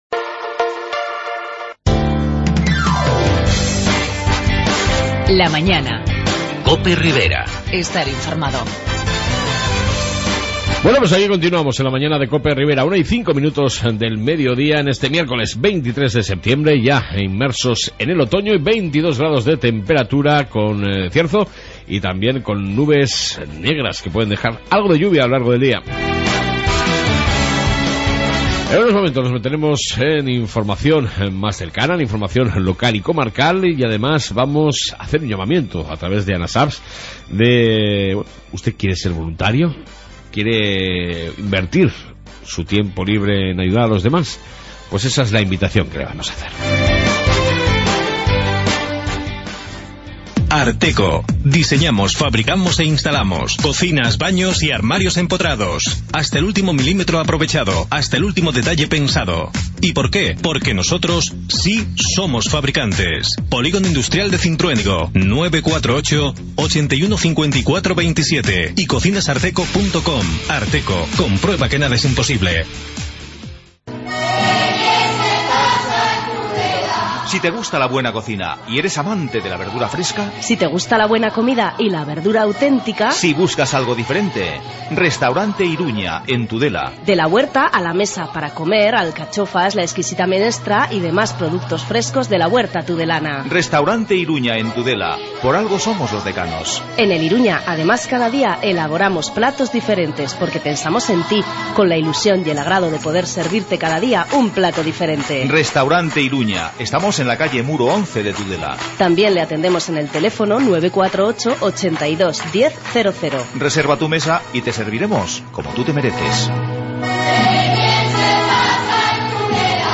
AUDIO: En esta 2 parte, amplio Informativo Ribero Y Hablamos con ANASAPS (Asociación navarra para la salud mental) en busqueda de voluntariado.